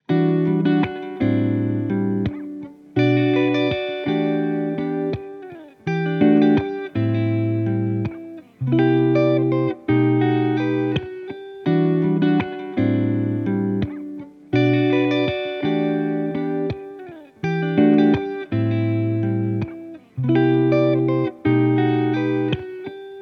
• 18 authentic guitar loops (with Stems: 90 total samples)